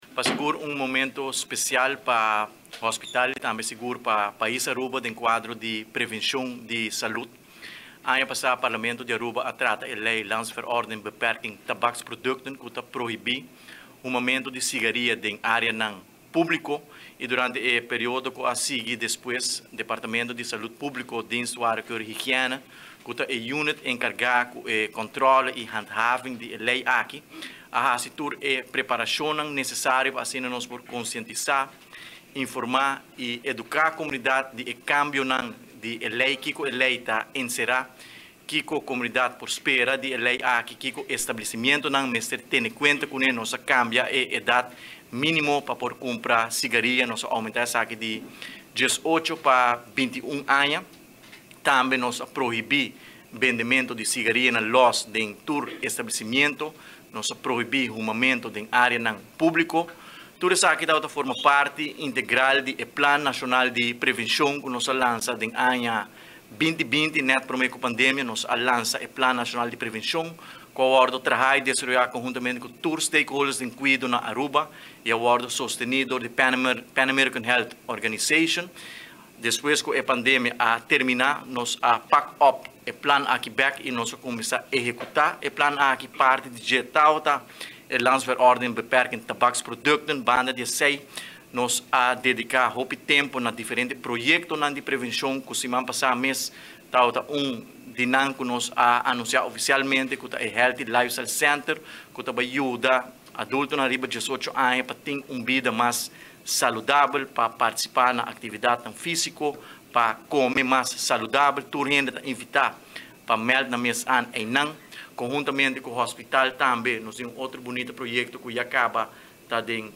Na hospital a tuma luga un conferencia di prensa na unda cu oficialmente a anuncia cu desde prome di Maart 2023 no mag di huma mas na dr. Horacio Oduber Hospital, ni tampoco riba e tereno rond.
Asina minister di Salud Publico Dangui Oduber a elabora den e conferencia di prensa